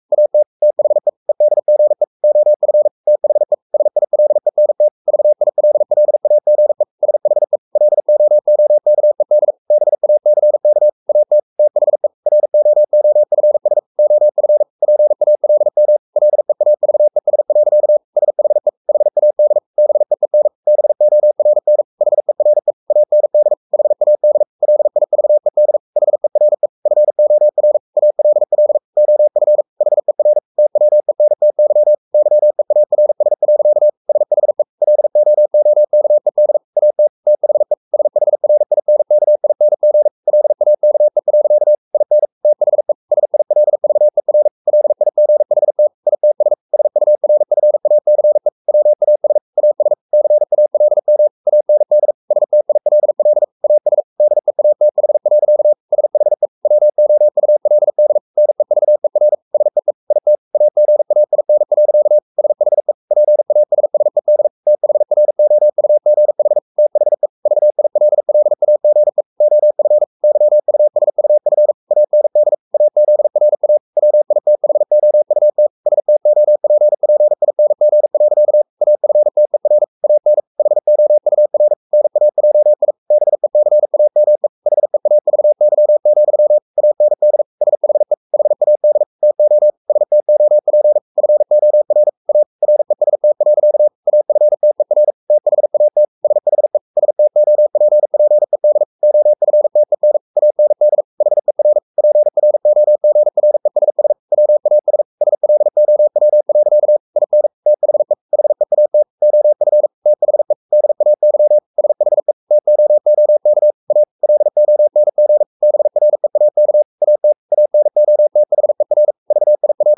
Never 43wpm | CW med Gnister